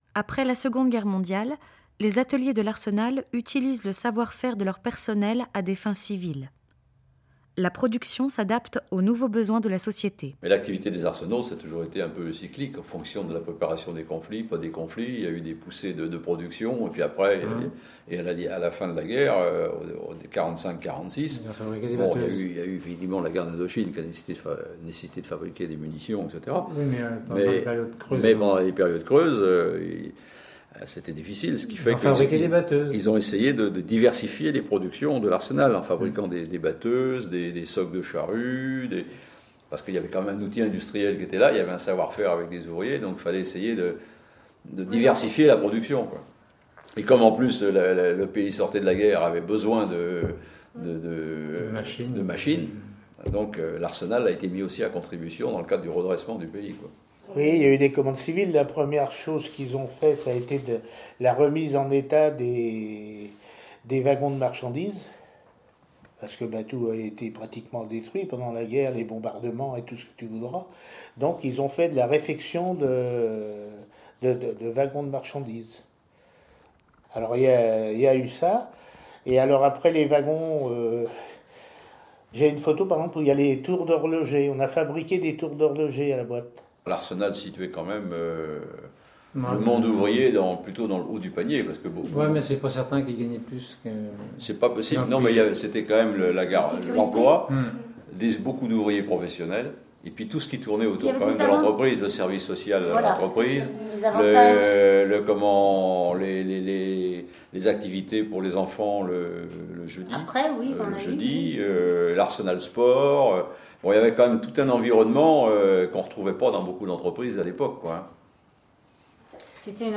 Témoignages